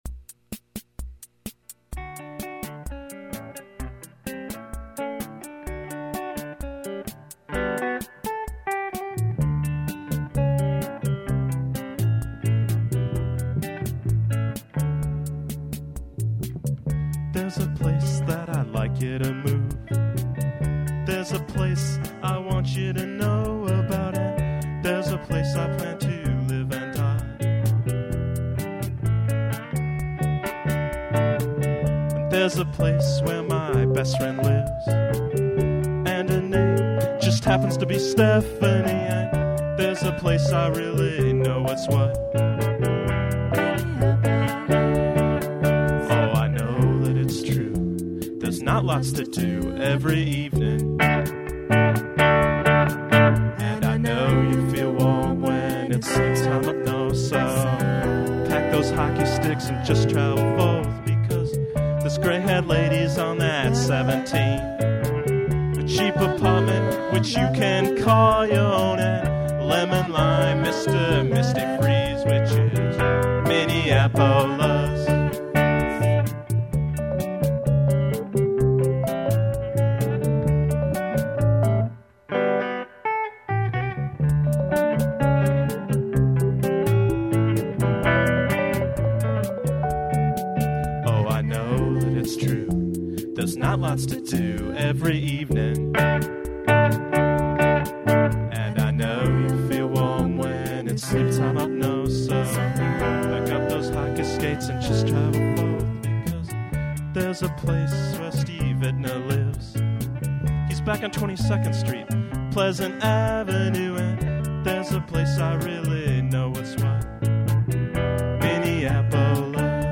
the band plays along to a drum machine
lovely live version